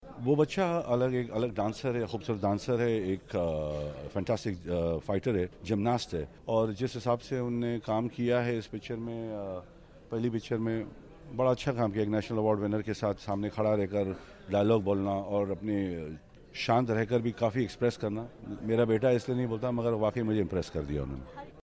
Jackie Shroff is talking about his son actor Tiger Shroff.